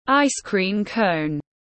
Kem ốc quế tiếng anh gọi là ice-cream cone, phiên âm tiếng anh đọc là /ˌaɪs ˈkriːm ˌkəʊn/
Ice-cream cone /ˌaɪs ˈkriːm ˌkəʊn/